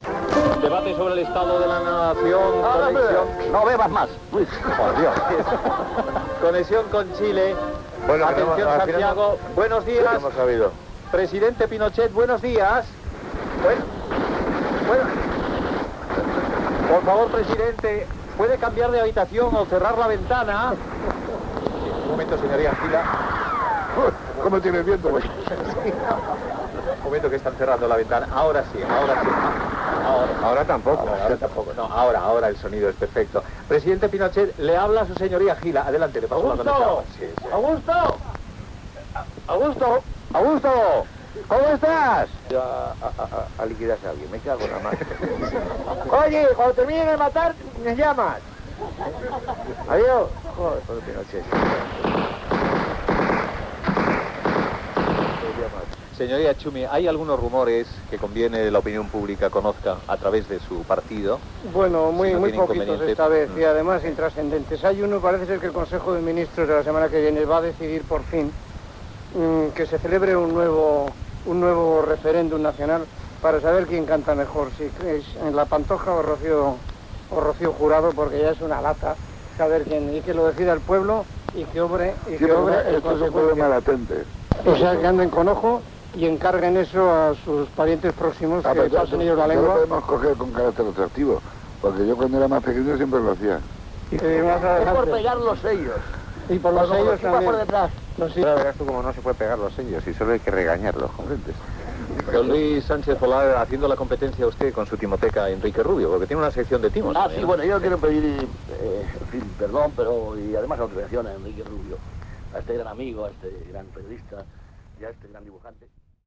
Fragment de l'espai "El estado de la nación" amb Miguel Gila,Chumi Chúmez, José Luis Coll i Luis Sánchez Polack
Info-entreteniment